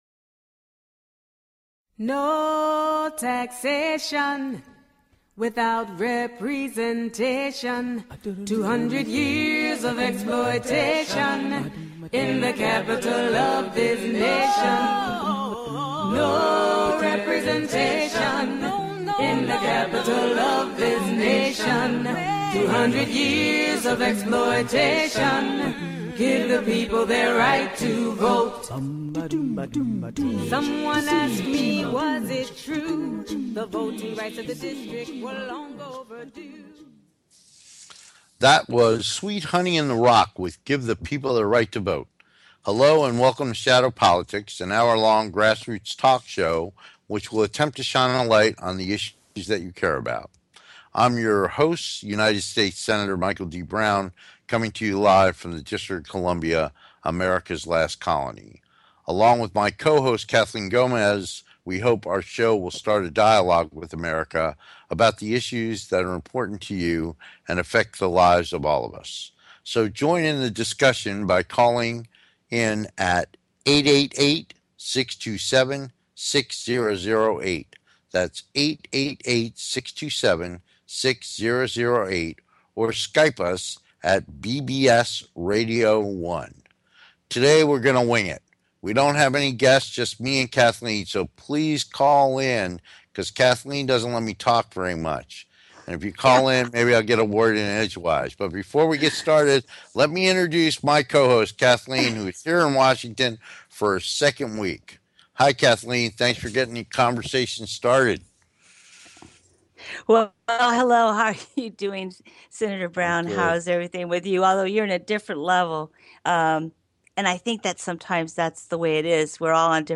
Talk Show Episode
We look forward to having you be part of the discussion so call in and join the conversation.